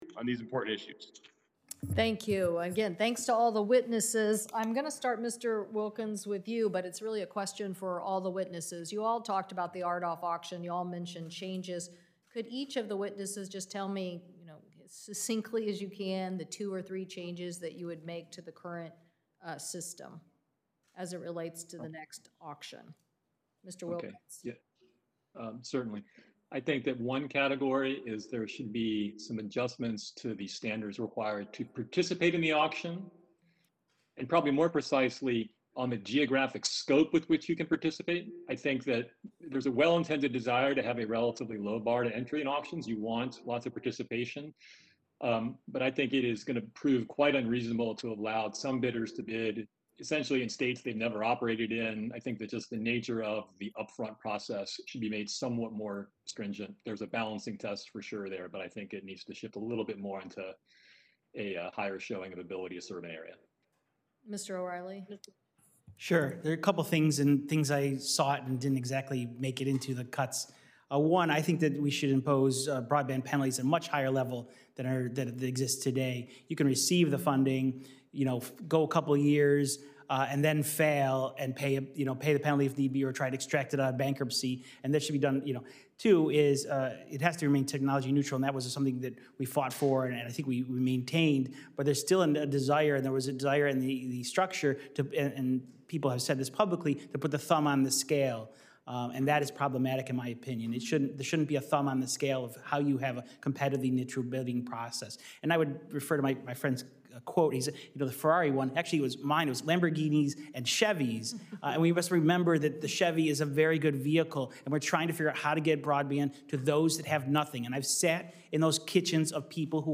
Video of Chair Cantwell’s Q&A with witnesses can be found HERE and audio is